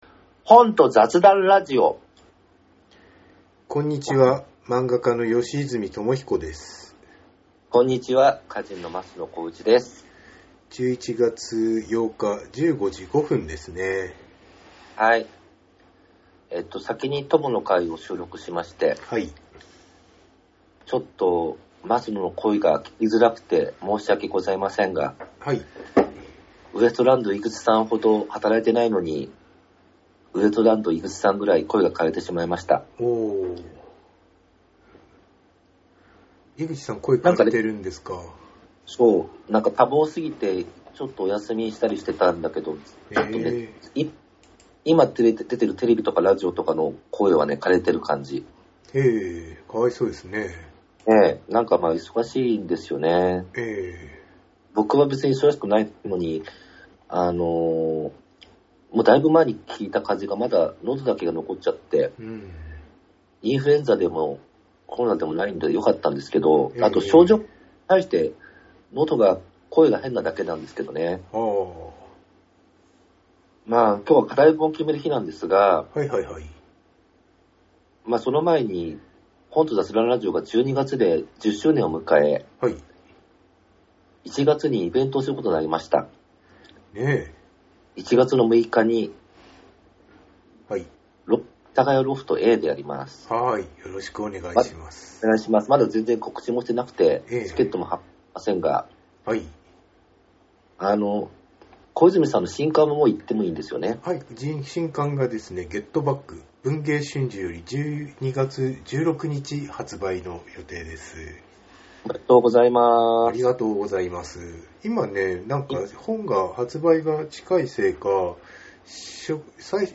11月8日teamsにて収録